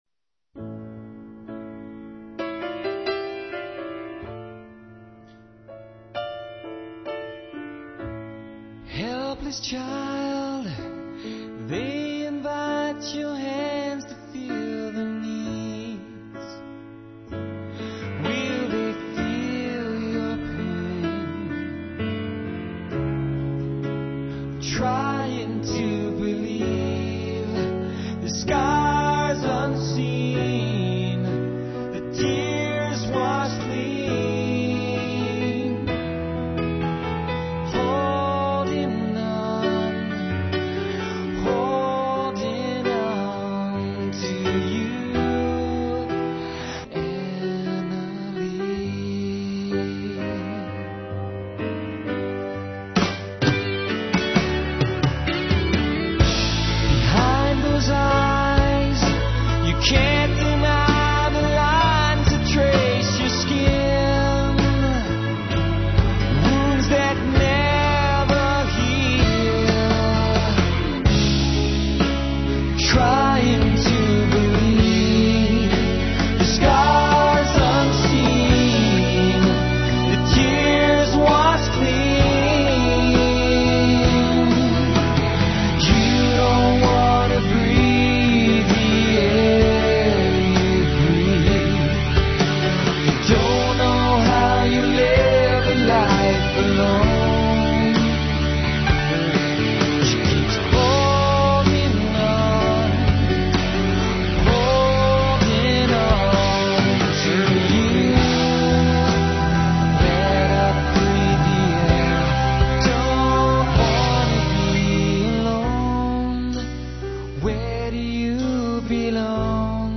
one of those great piano ballads.